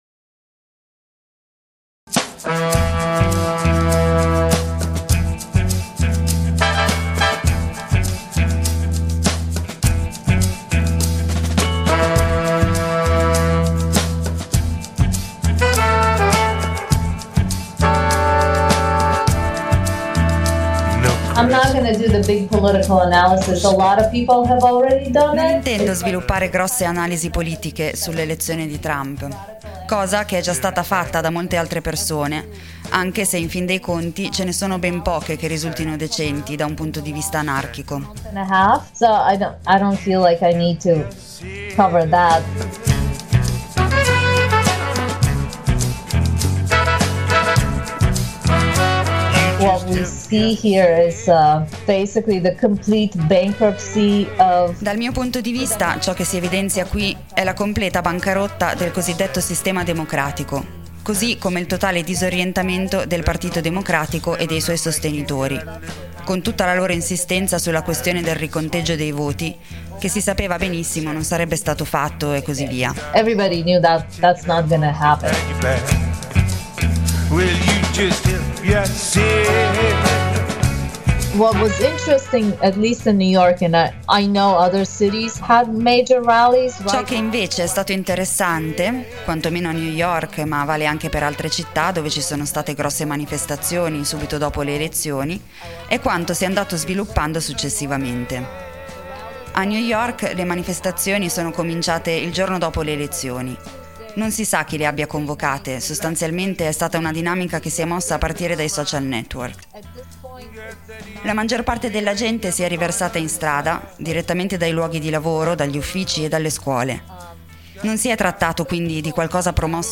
Ne parliamo con una compagna, che ci racconta ciò che sta già accadendo e quel che può avvenire nel prossimo futuro.